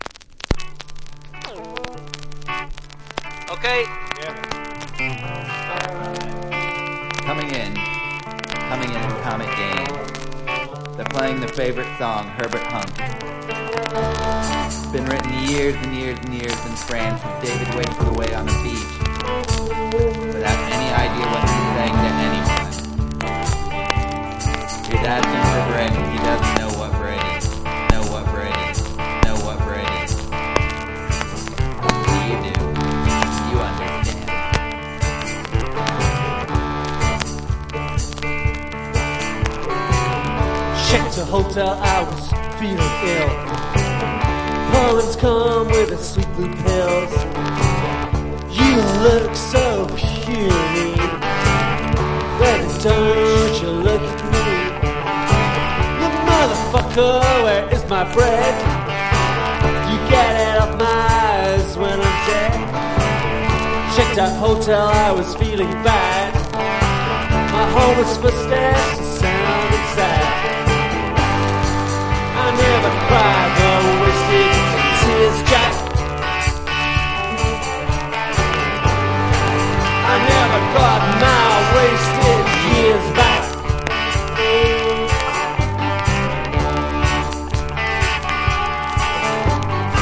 1. 90'S ROCK >
NEO ACOUSTIC / GUITAR POP (90-20’s)